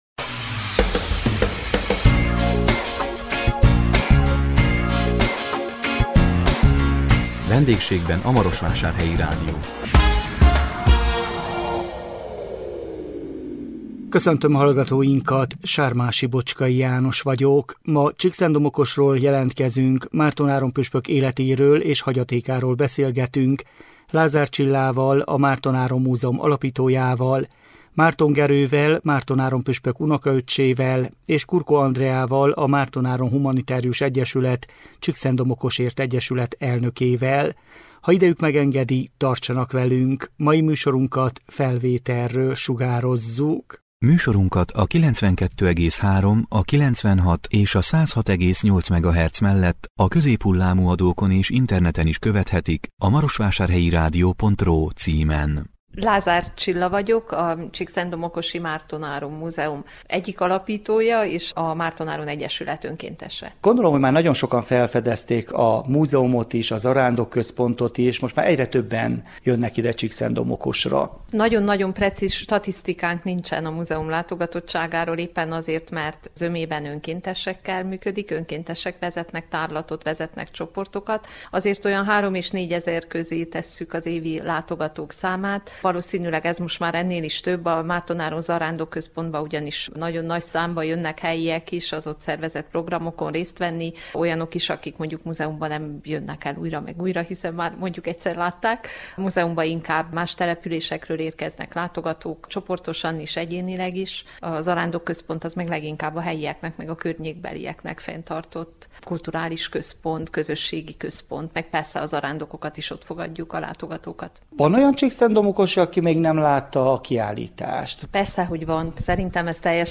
A 2025 augusztus 7-én közvetített VENDÉGSÉGBEN A MAROSVÁSÁRHELYI RÁDIÓ című műsorunkkal Csíkszentdomokosról jelentkeztünk